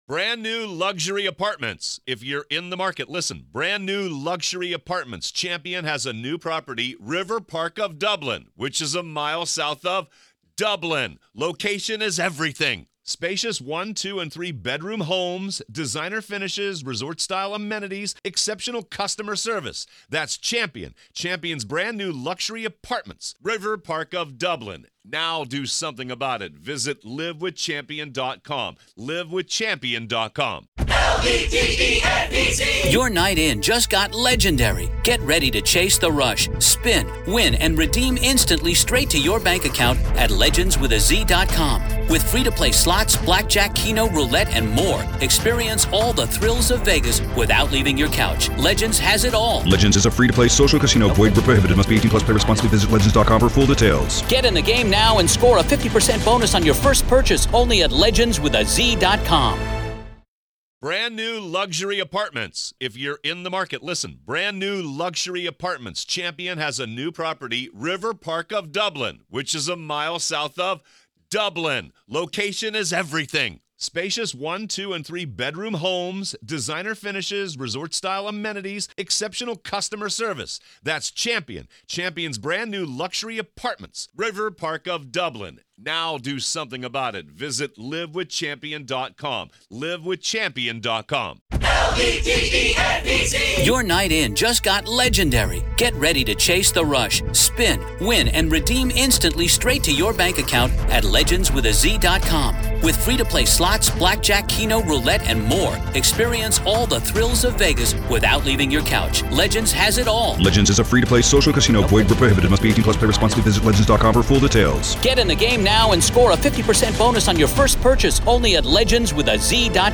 we find ourselves inside the courtroom